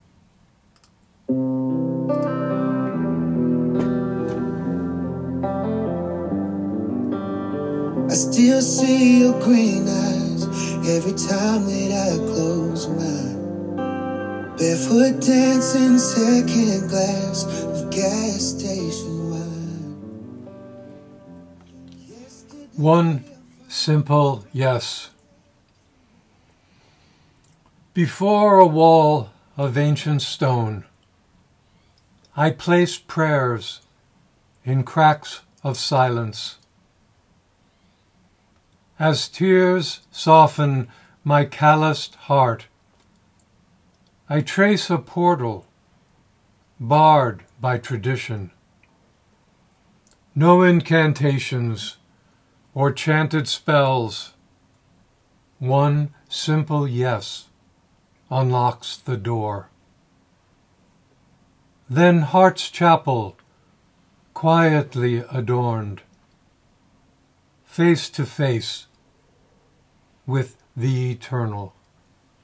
Reading of “One Simple Yes” with music by Teddy Swims